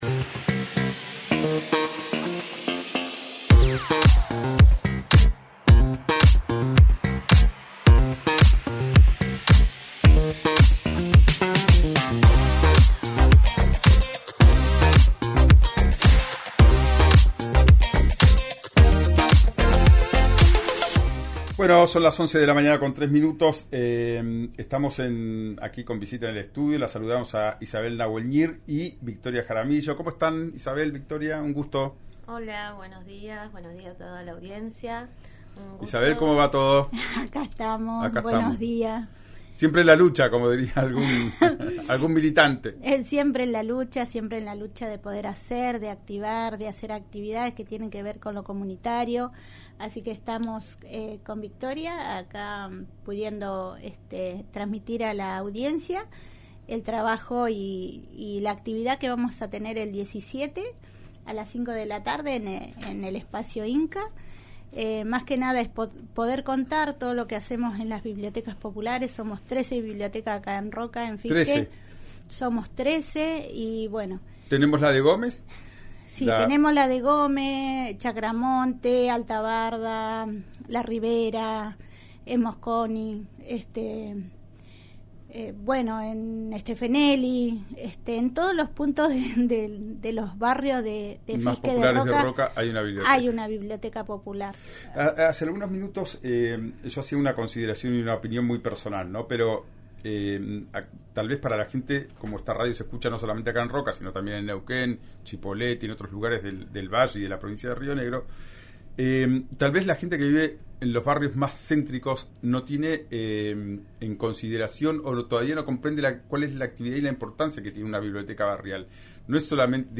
En una entrevista realizada en el programa «Ya es tiempo», explicaron que las bibliotecas se han ido transformando con las herramientas de la tecnologías. Hoy las personas no van a buscar solo libros, sino también se acercan a realizar trámites, cursos, juegos y mucho más.